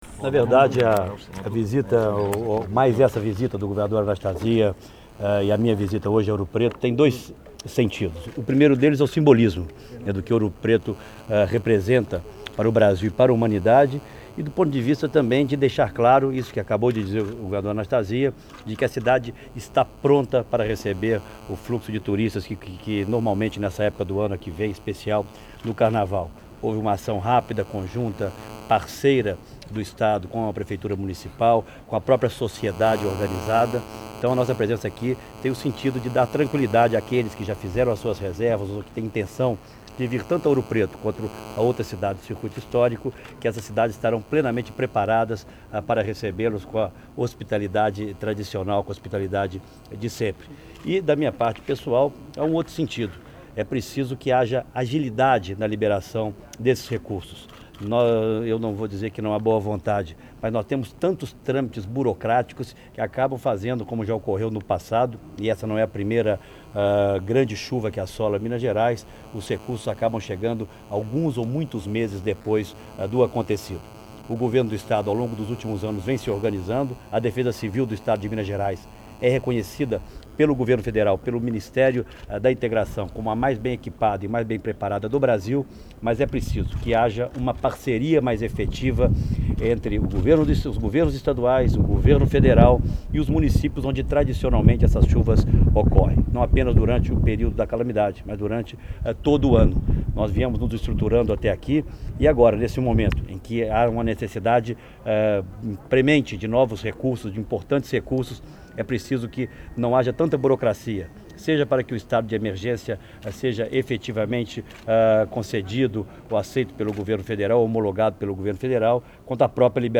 Entrevista de Aécio Neves durante visita a Ouro Preto